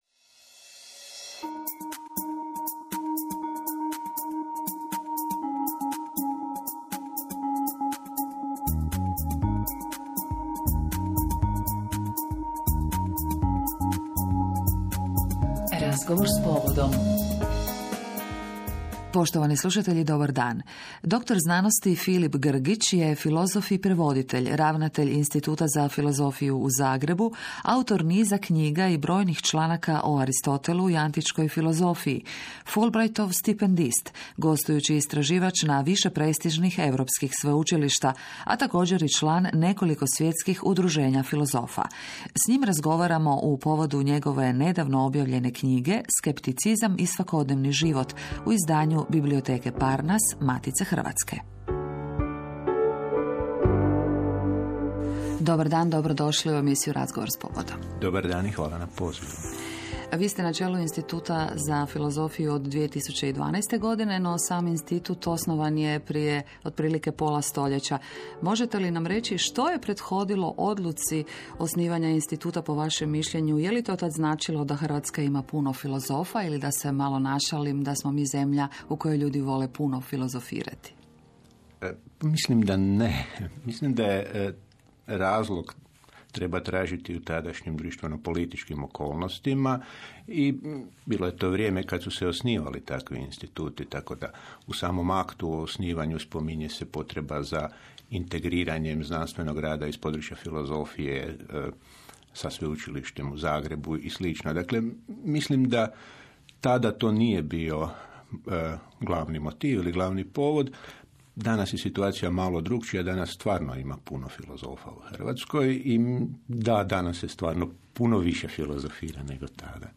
Odjeci